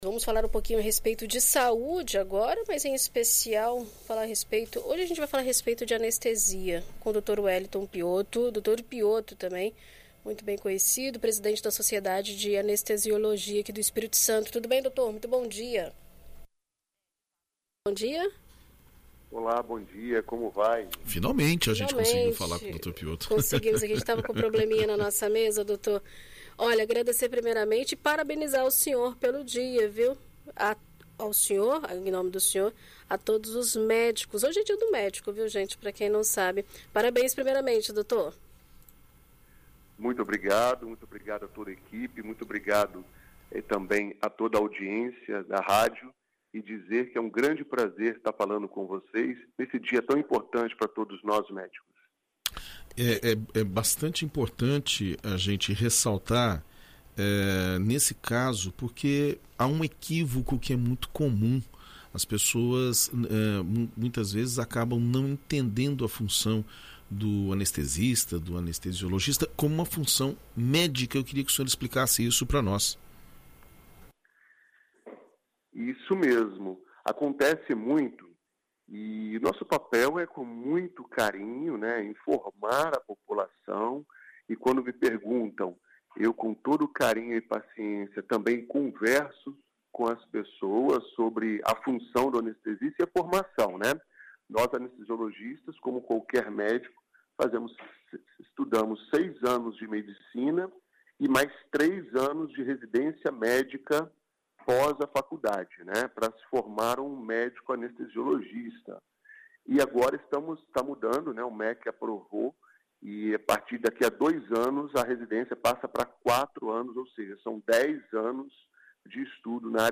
Em entrevista a BandNews FM Espírito Santo nesta quarta-feira (19)